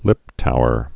(lĭptouər)